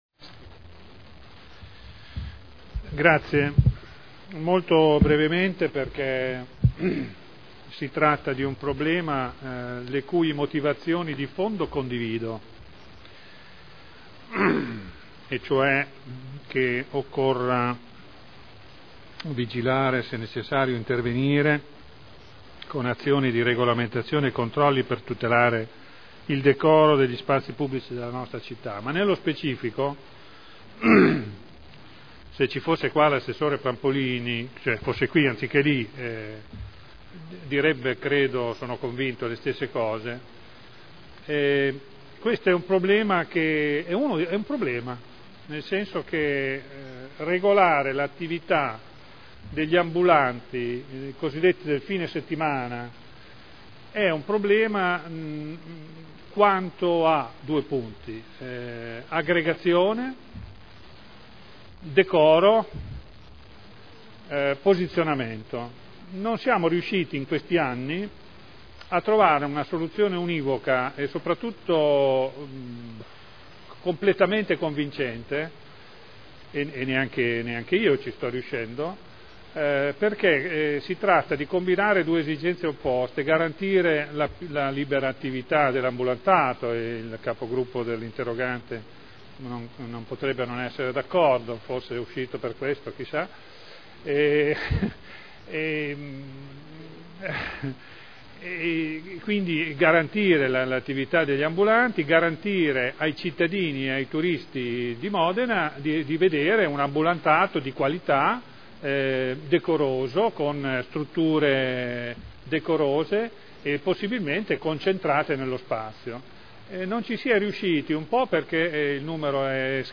Graziano Pini — Sito Audio Consiglio Comunale
Seduta del 10/12/2009.